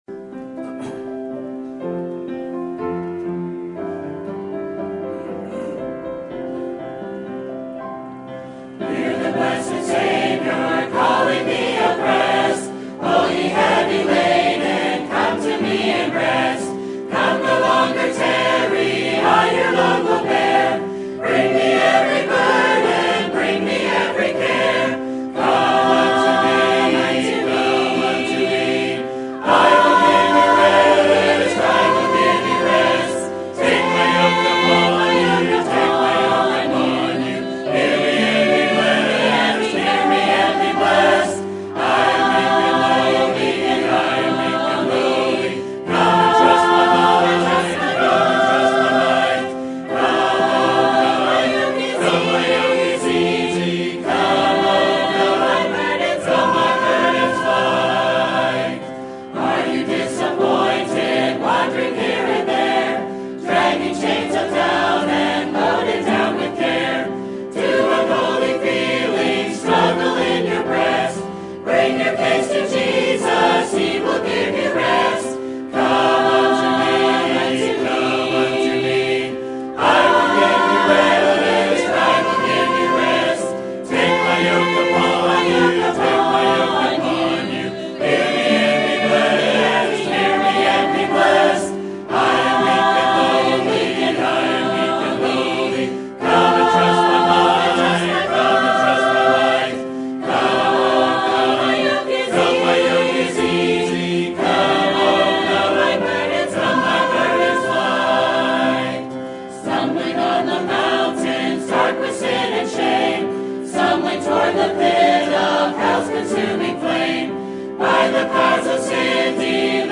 Sermon Topic: General Sermon Type: Service Sermon Audio: Sermon download: Download (30.4 MB) Sermon Tags: James Grace More Pride